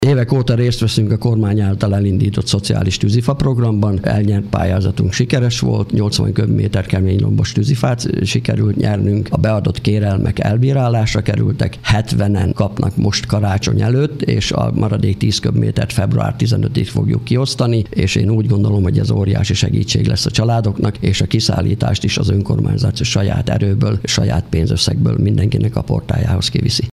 Az ősz folyamán megnyerték a lehetőséget a polgármesteri hivatal és a sportöltöző energetikai felújítására, egy új mini bölcsőde építésére és a művelődési ház sportcsarnok és könyvtár részének fejlesztésére. Sipeki Zsolt polgármester elmondta, a vonatkozó közbeszerzési eljárásokat körültekintően indították meg.